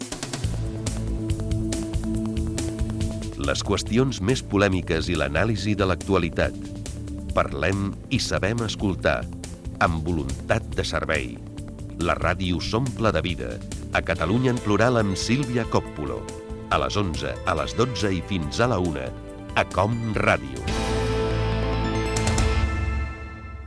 Promoció del programa
Entreteniment
FM